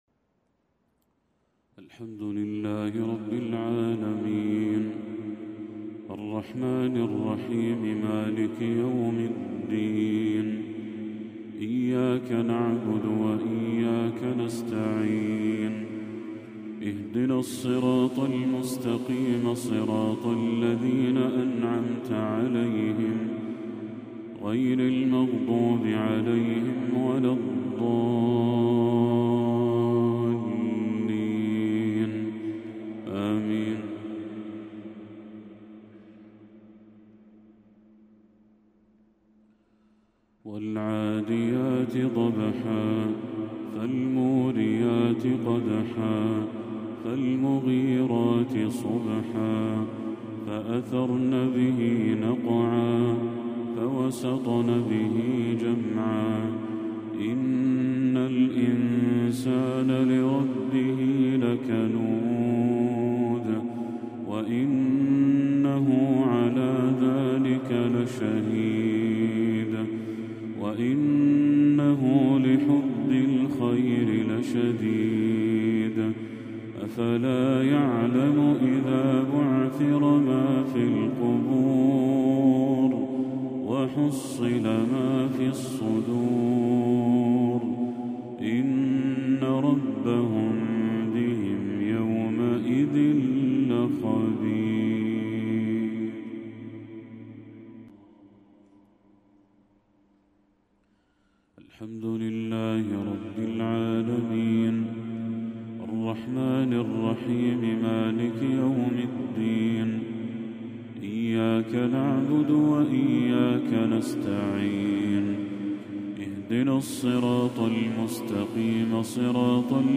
تلاوة لسورتي العاديات و الضحى للشيخ بدر التركي | مغرب 25 ربيع الأول 1446هـ > 1446هـ > تلاوات الشيخ بدر التركي > المزيد - تلاوات الحرمين